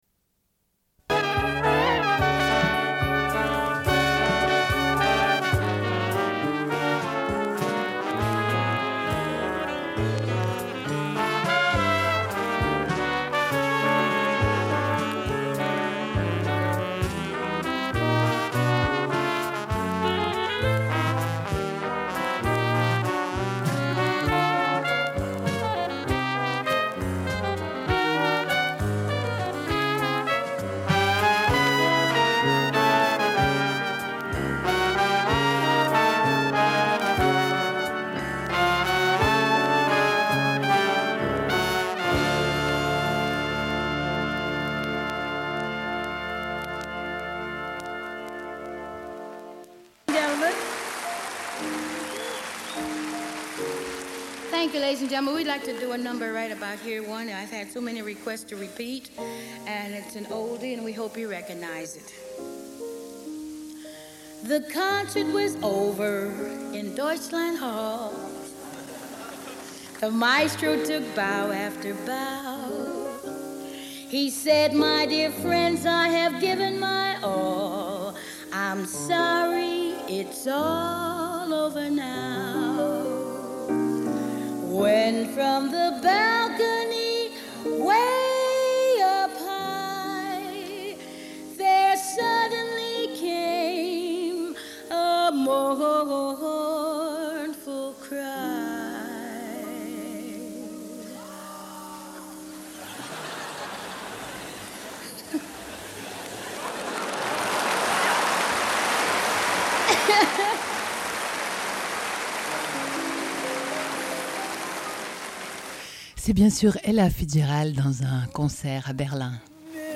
Suite de l'émission : chronique « Planète femmes », revue de presse sur les femmes. Différents sujets : femmes, sida et accouchement ; Exil et Centre femmes Lausanne ; femmes dans l'horlogerie ; une brochure de Viol Secours.
Une cassette audio, face A